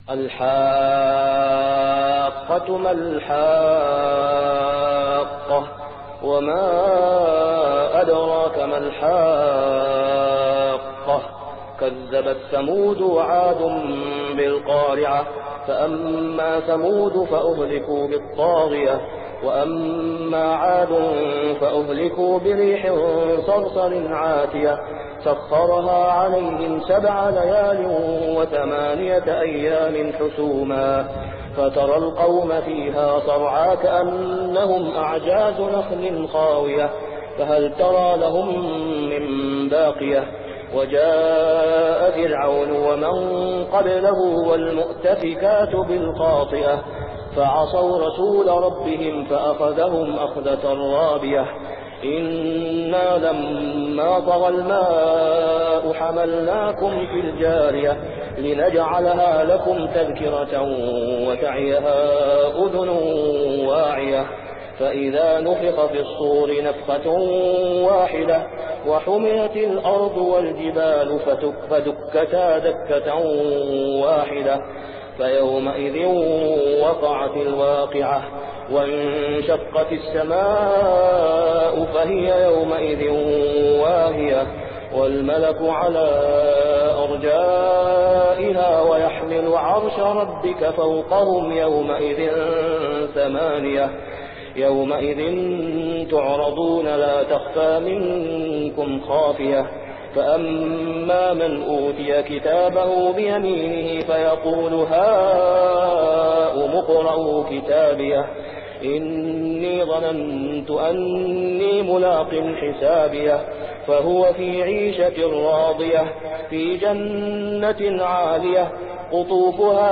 صلاة الفجر عام 1423هـ | سورة الحاقة كاملة | > 1423 🕋 > الفروض - تلاوات الحرمين